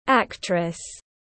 Nghệ sĩ diễn viên /ɑˈktrəs/